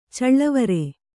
♪ caḷḷavare